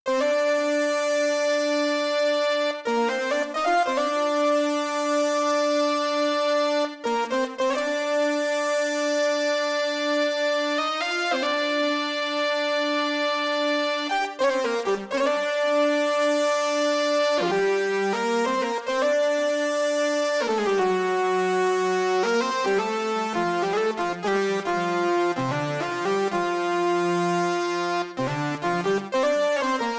07 sync lead C.wav